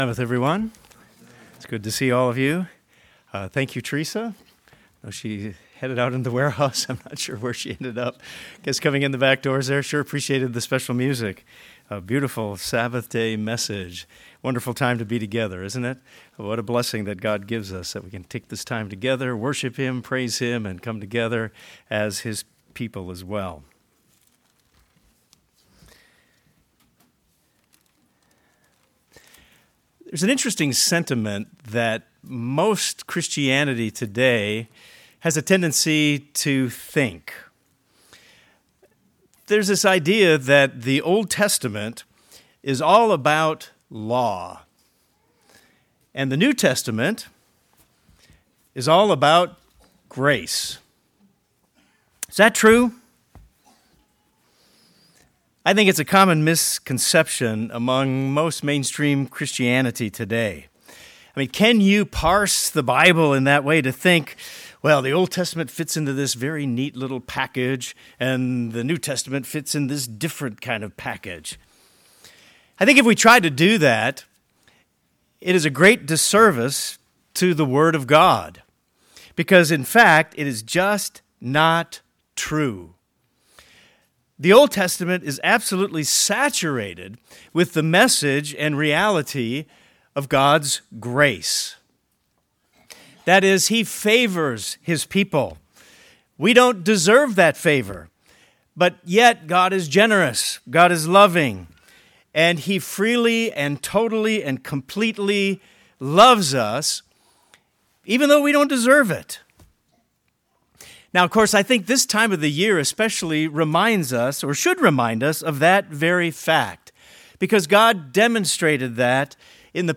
Symbolically, we live between the Red Sea and the Promised Land. This sermon identifies how God led them and emphasizes how He leads us on our spiritual journey by His grace.